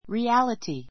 reality riǽləti リ ア りティ 名詞 複 realities riǽlətiz リ ア りティ ズ 現実（性）, 実在（性） His dream of having his own house became a reality.